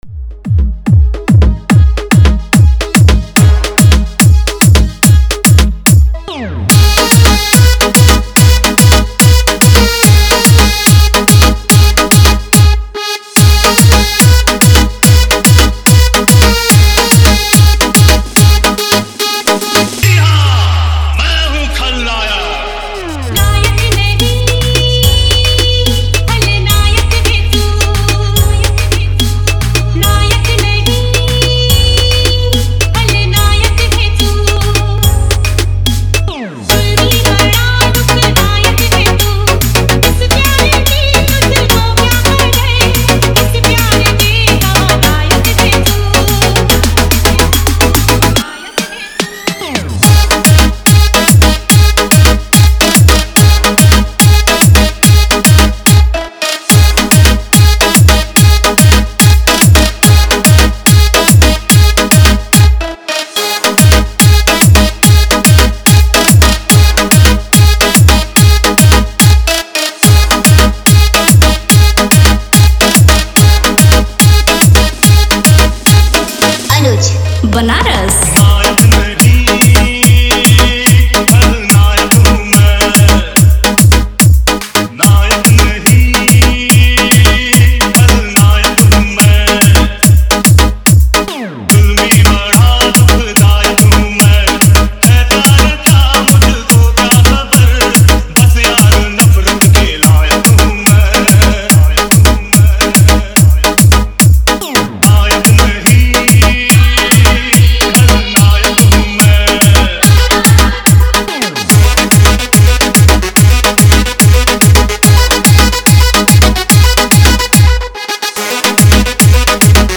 2025 Bhojpuri DJ Remix - Mp3 Songs